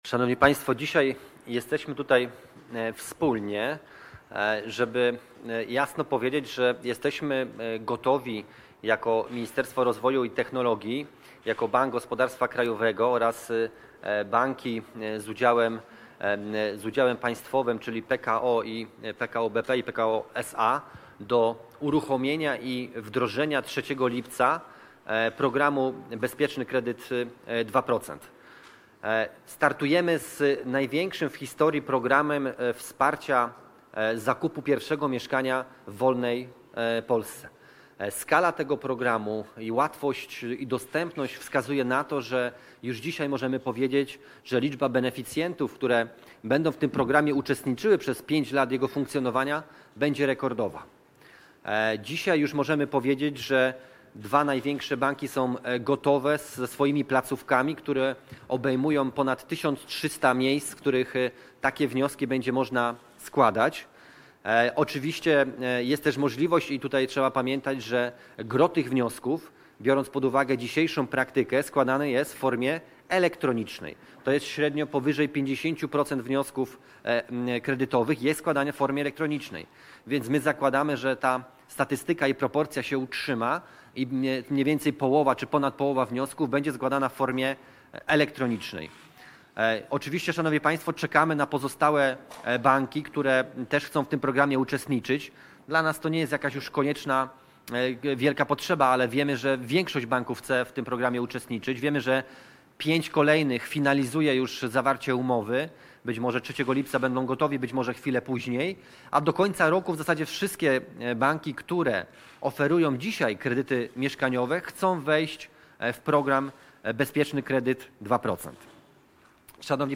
Wypowiedź ministra Waldemara Budy